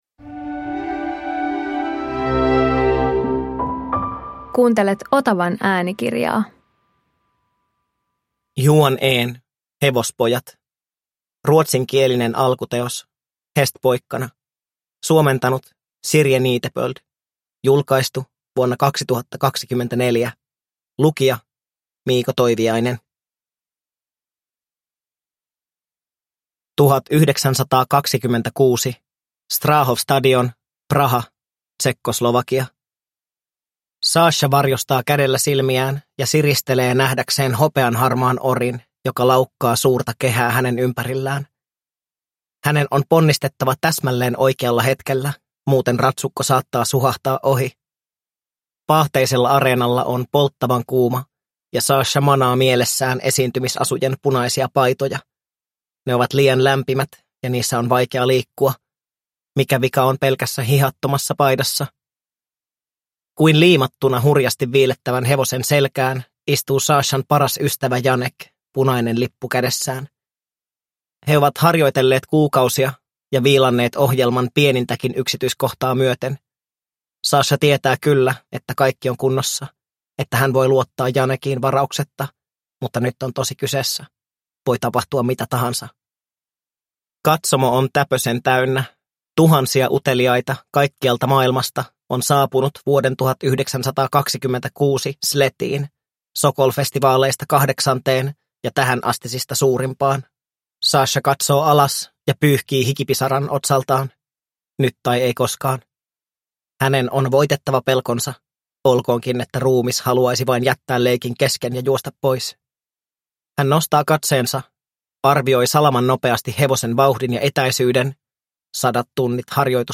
Hevospojat – Ljudbok